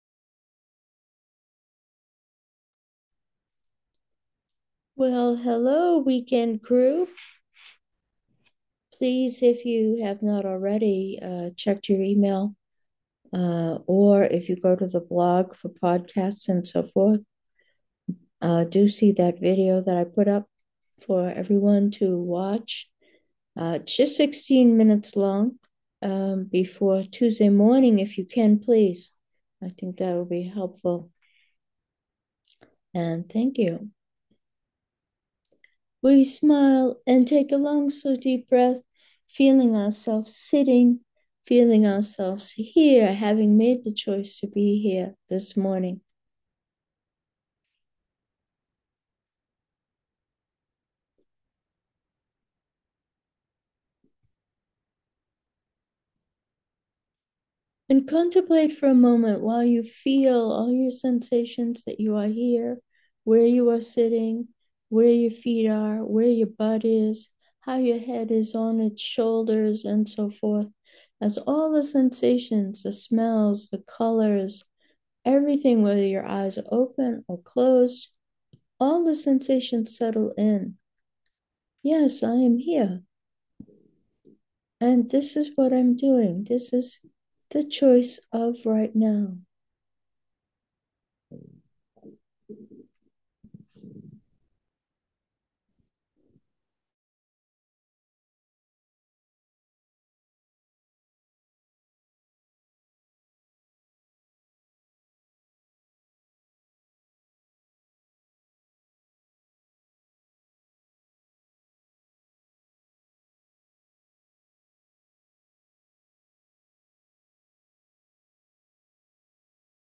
A gentle meditation of abiding in the choice to meditate, to learn to meditate, and to vibrate goodness into the world.